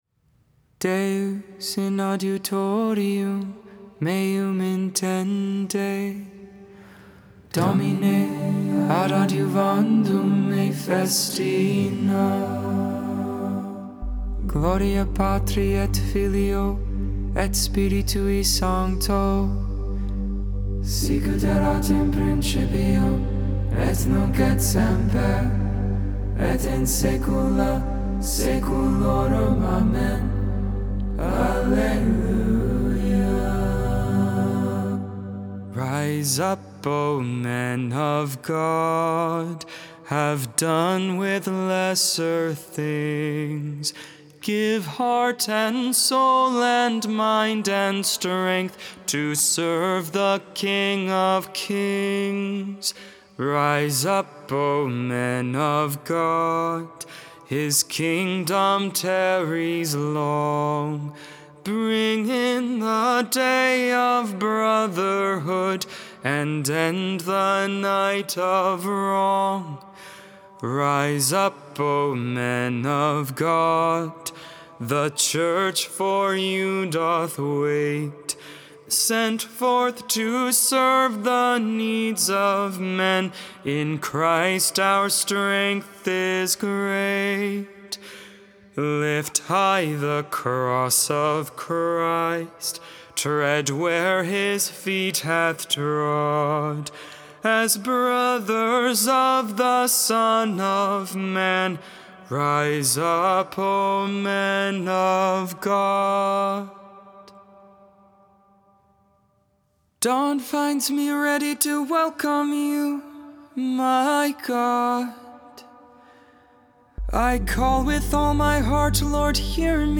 12.3.21 Lauds, Saturday Morning Prayer